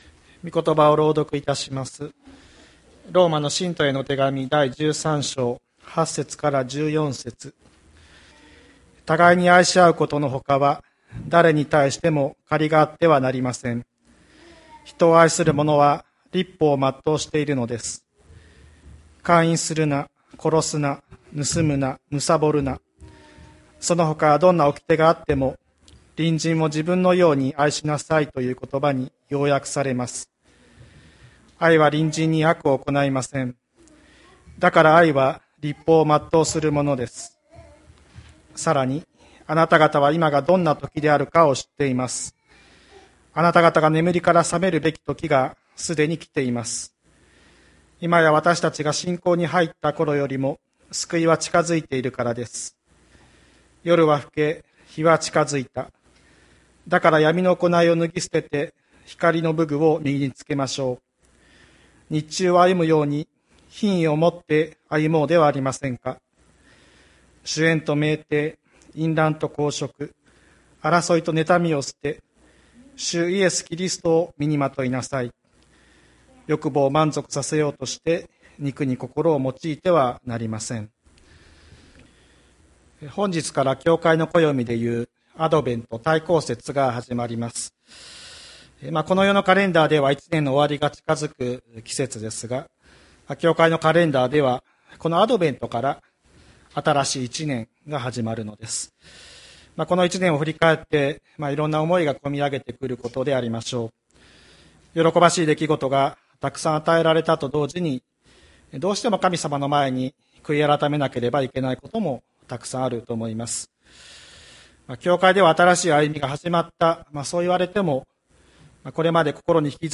千里山教会 2021年11月28日の礼拝メッセージ。